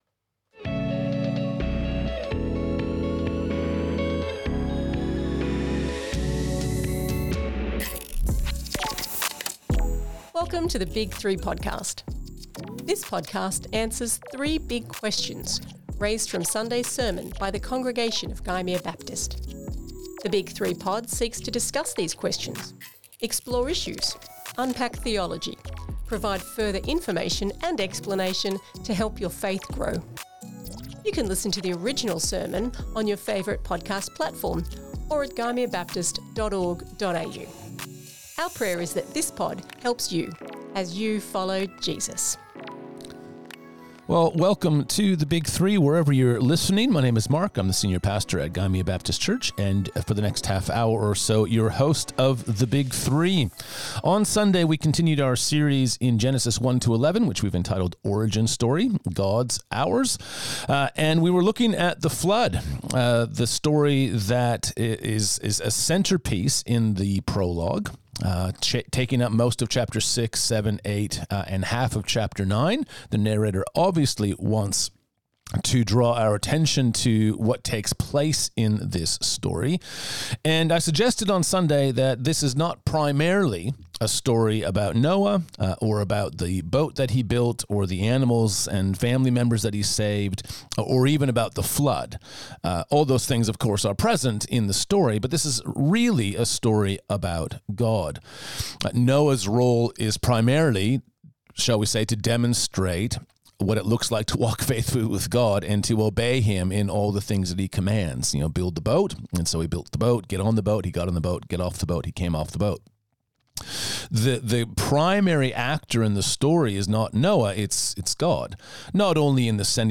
2025 Current Sermon The Big 3